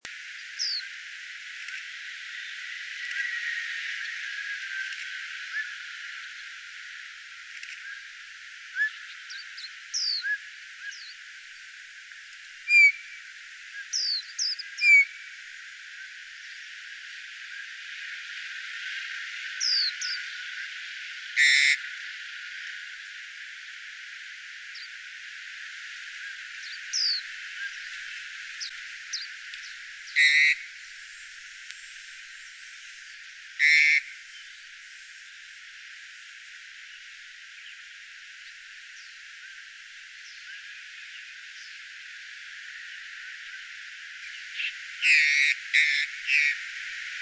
2013鐵道路烏領椋鳥3.mp3
黑領椋鳥 Gracupica nigricollis
錄音地點 高雄市 鼓山區 鐵道
錄音環境 鐵道旁喬木
4-5隻一群